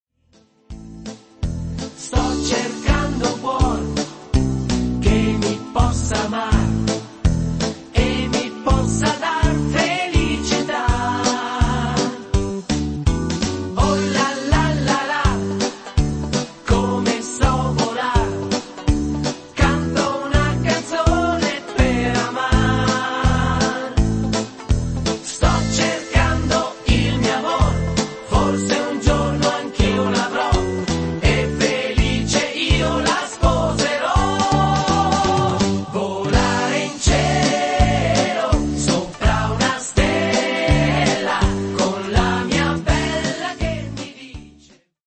duinato